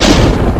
imp_ball_impact.wav